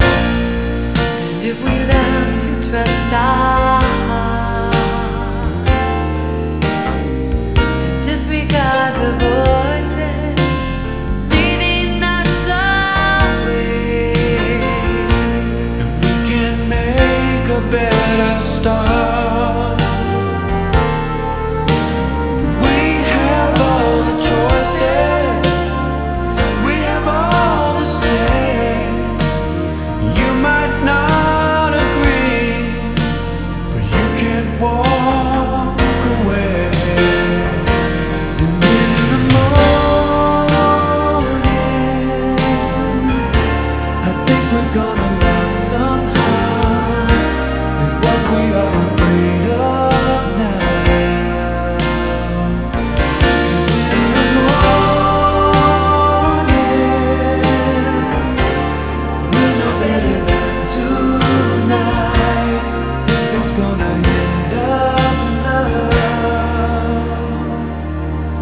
lead vocals